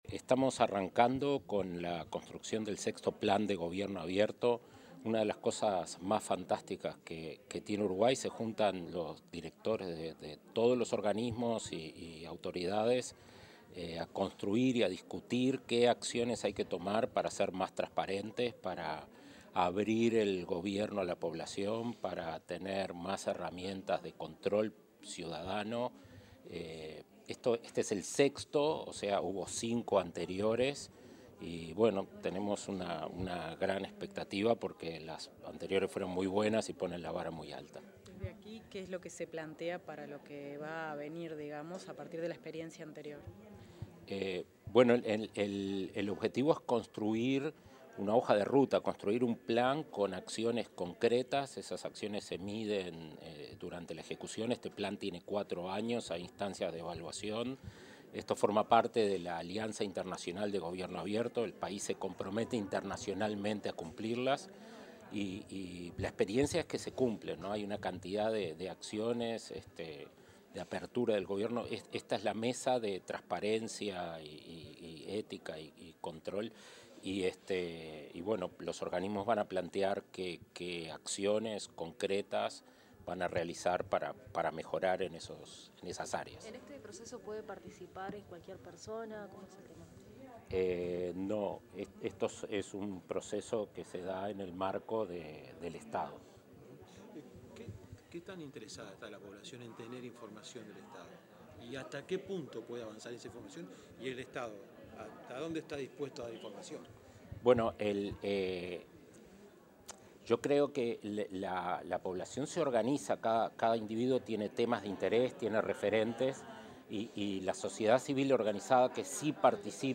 Declaraciones del director ejecutivo de Agesic, Daniel Mordecki
Declaraciones del director ejecutivo de Agesic, Daniel Mordecki 30/05/2025 Compartir Facebook X Copiar enlace WhatsApp LinkedIn El director ejecutivo de la Agencia de Gobierno Electrónico y Sociedad de la Información y del Conocimiento (Agesic), Daniel Mordecki, brindó declaraciones a la prensa, en el marco de la primera mesa de diálogo en el proceso de creación del Sexto Plan Nacional de Gobierno Abierto.